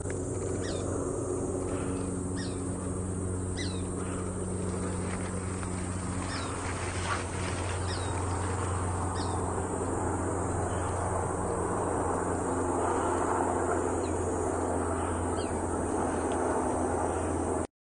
松雀鹰叫声音频收集